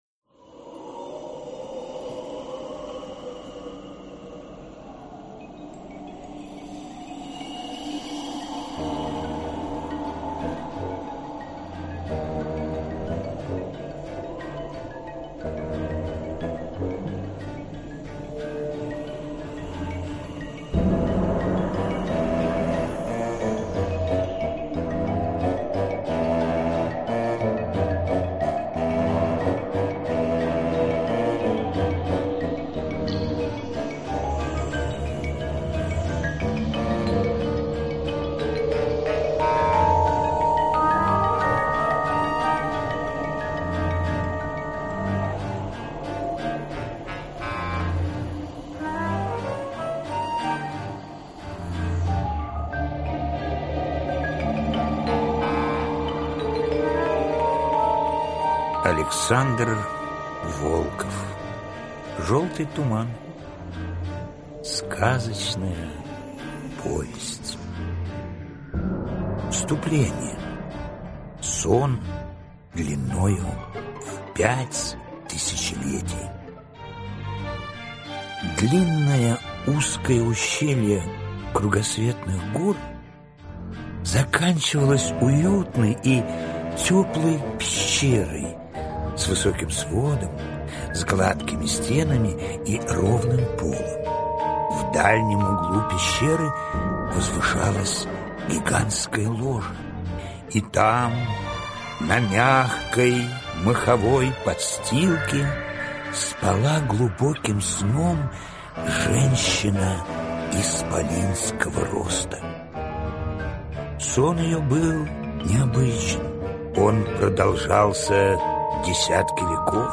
Аудиокнига Жёлтый Туман | Библиотека аудиокниг